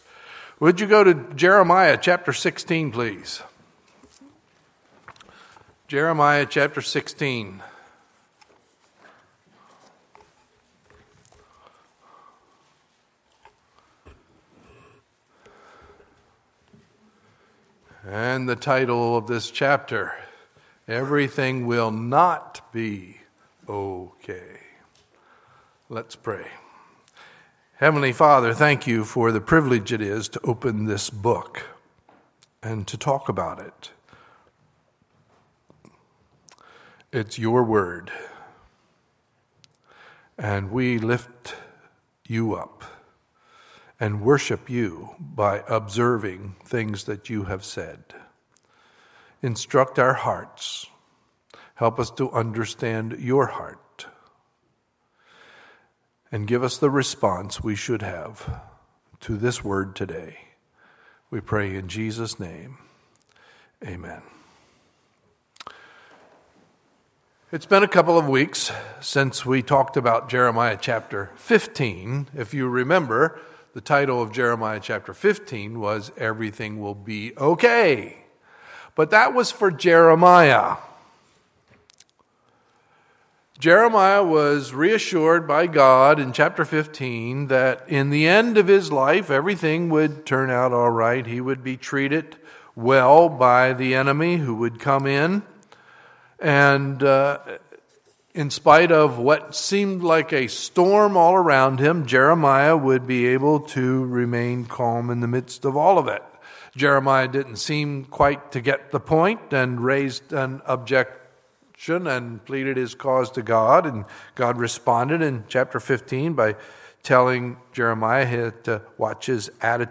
Sunday, May 24, 2015 – Sunday Morning Service
Sermons